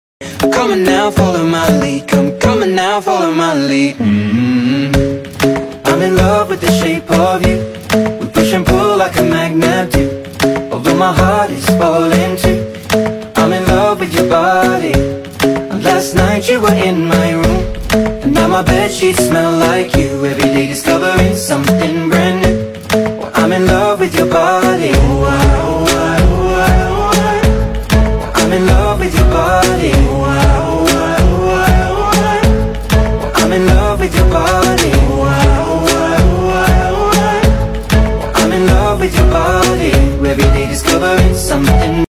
Digital Wireless Conference System sound effects free download